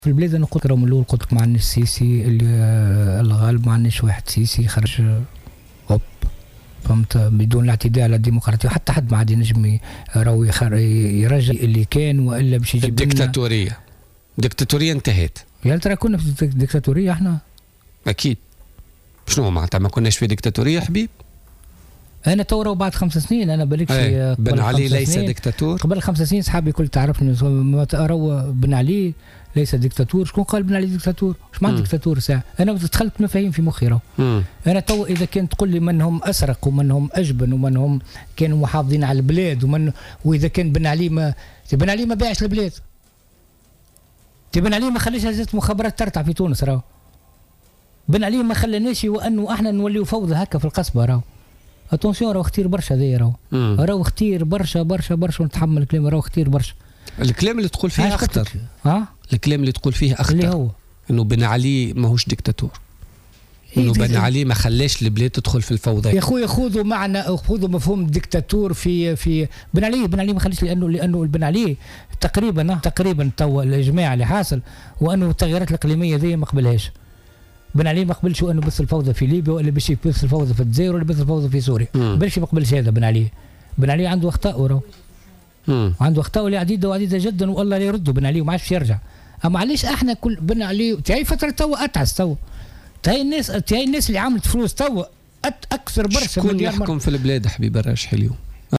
ضيف برنامج بوليتكا